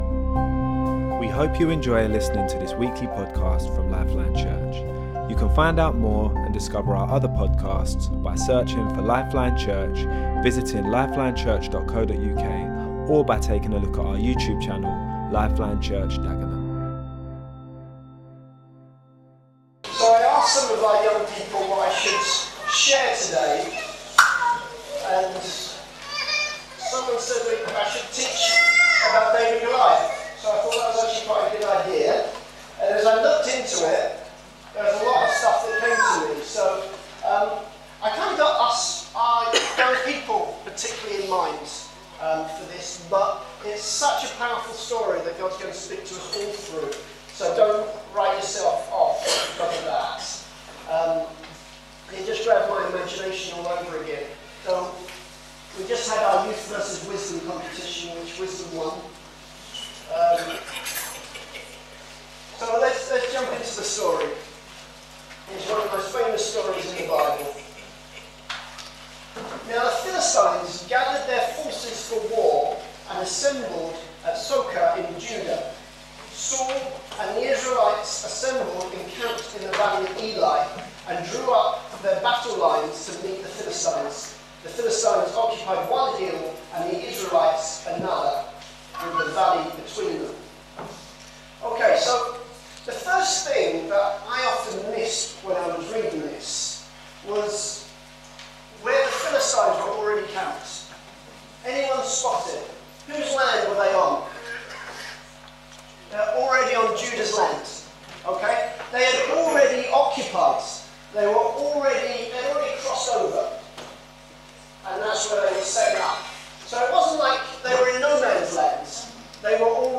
Talks from LifeLine Church's weekly meeting - released every Monday